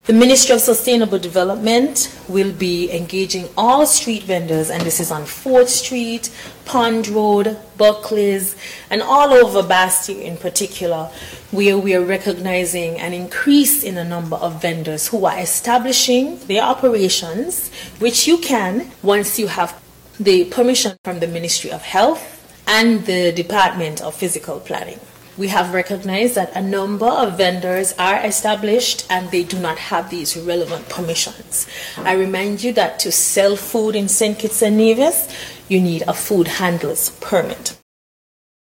On Thursday Feb. 12th, during a National Assembly Sitting, the Hon. Dr. Joyelle Clarke, acknowledged that vending remains a source of income for many families but stressed that it must operate within legal requirements.
Minister of Sustainable Development and Environment-St. Kitts, Joyelle Clarke.